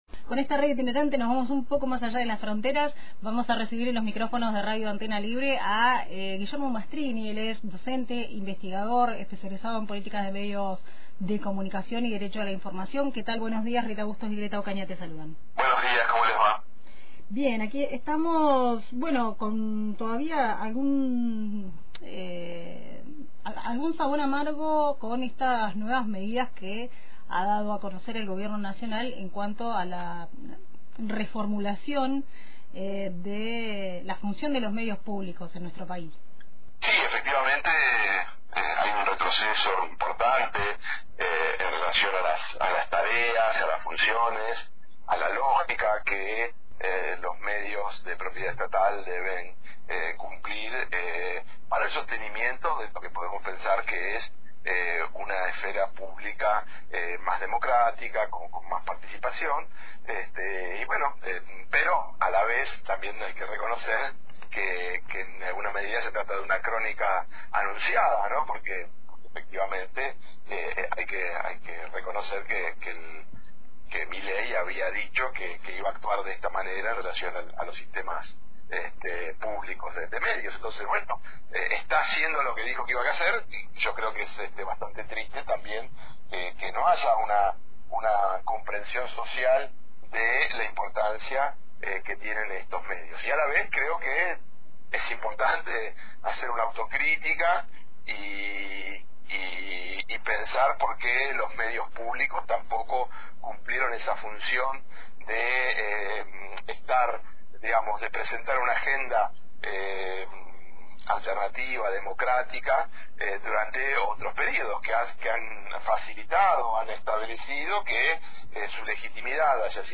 Escucha la entrevista completa acá: